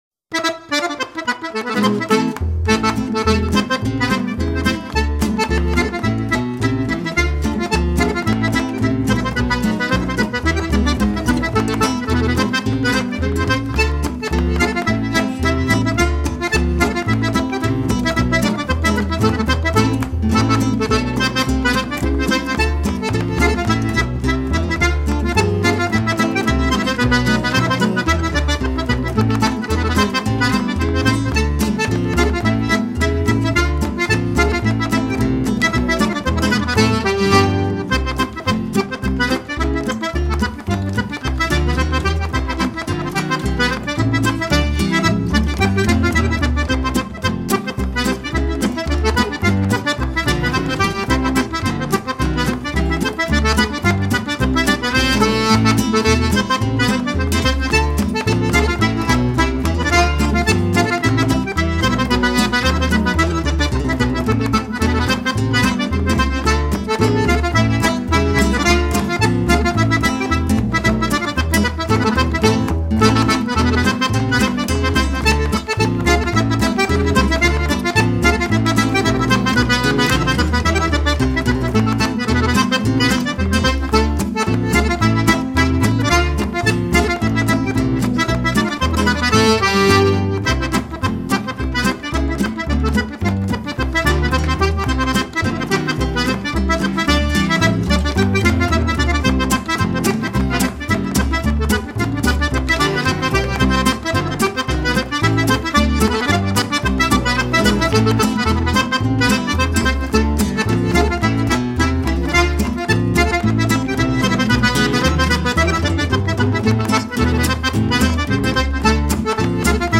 unique blend of reeds and textured chord arrangements
the lyrical, laid-back «swing» typical of the Québec region